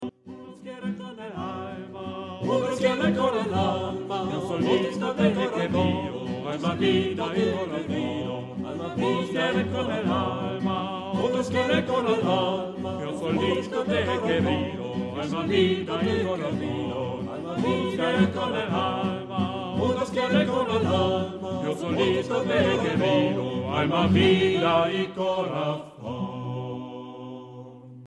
Género/Estilo/Forma: Canon ; Jazz ; Ostinato ; Profano
Tipo de formación coral:  (2 voces iguales )
Tonalidad : do menor